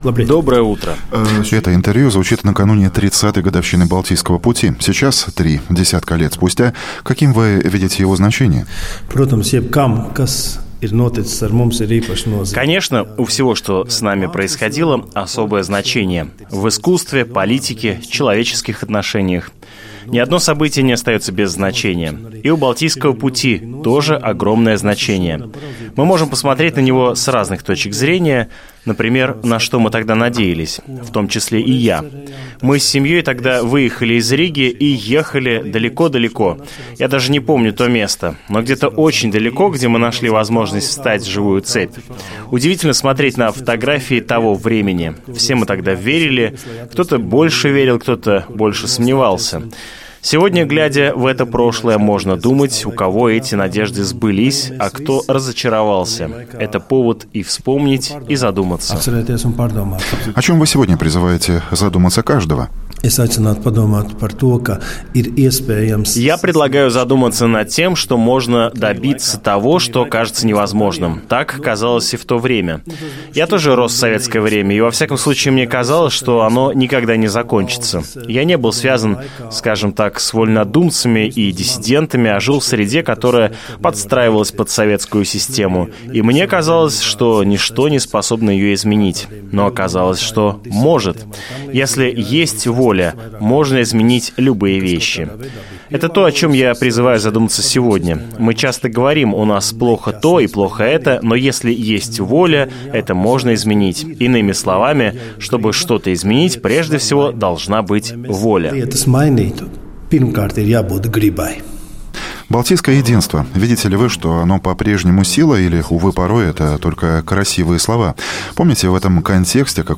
В интервью утренней программе Латвийского радио 4 "Домская площадь" Пунтулис также поделился своим видением политики интеграции общества, об открытой латышскости, будущем СМИ на русском языке и поддержке культуры национальных меньшинств.